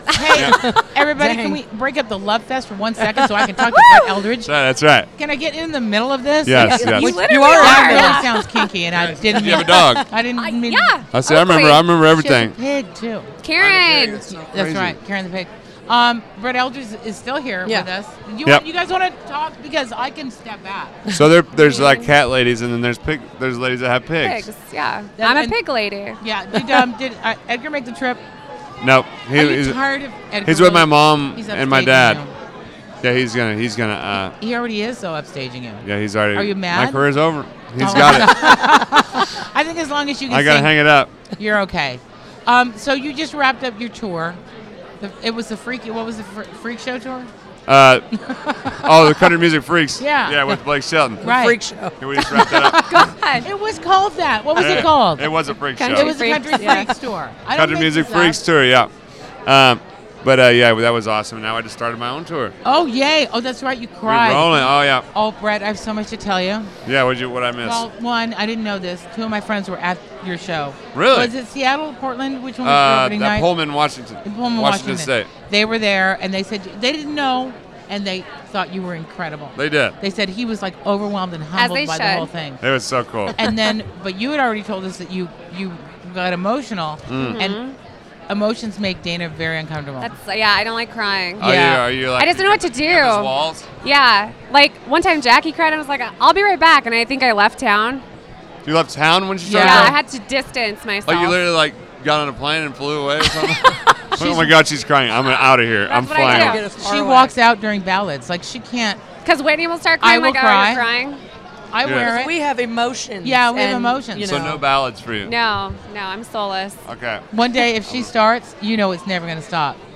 The Big Time with Whitney Allen talks with Brett Eldredge at the 53rd annual Academy of Country Music Awards.